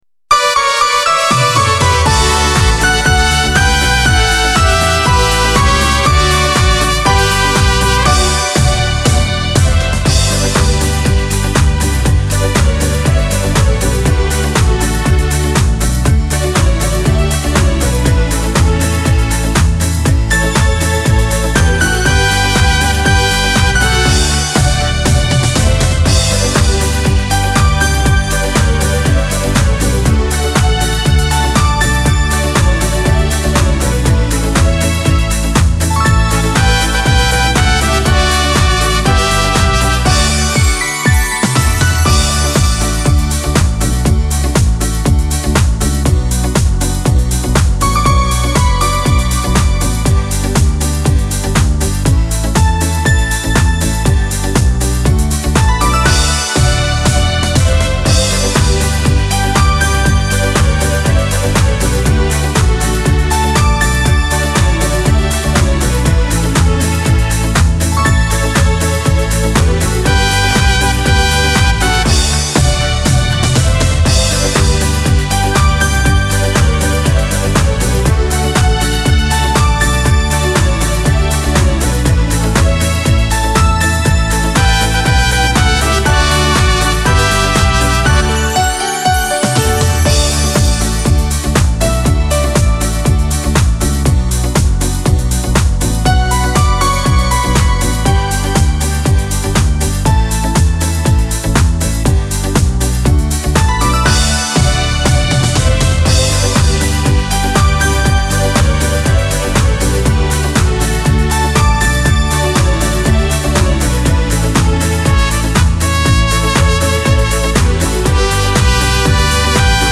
Hörprobe Karaoke-Version:
• Eingängige Melodie
• Fröhliche Bläser-Elemente
• Mitsingbare Vokalpassagen wie „La-la-la“